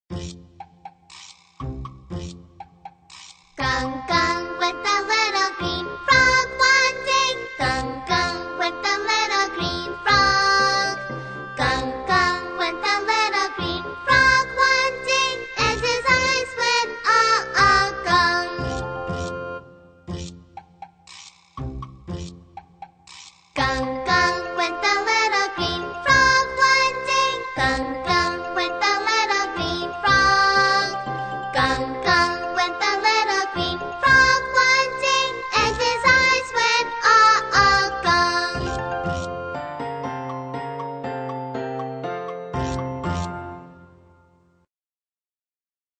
在线英语听力室英语儿歌274首 第123期:Little green frog的听力文件下载,收录了274首发音地道纯正，音乐节奏活泼动人的英文儿歌，从小培养对英语的爱好，为以后萌娃学习更多的英语知识，打下坚实的基础。